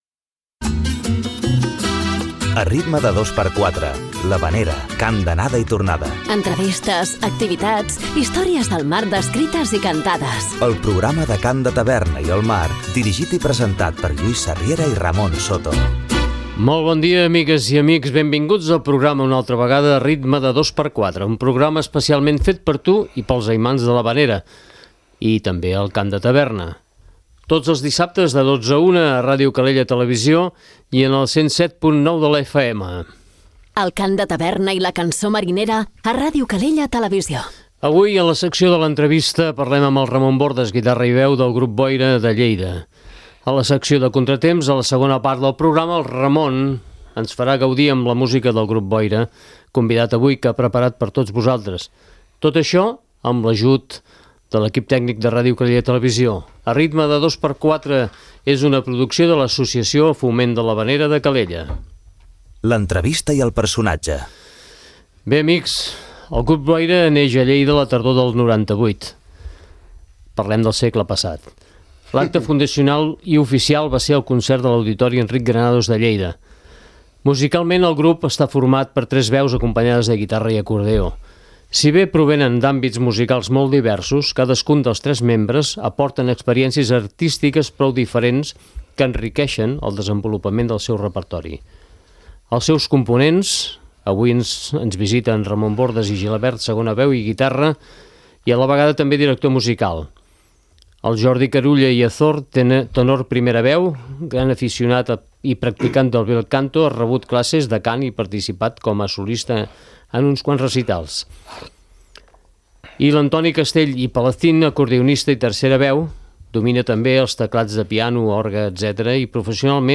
L'havanera, cant d'anada i tornada, entrevistes, activitats, històries del mar descrites i cantades; el programa del cant de taverna i el mar.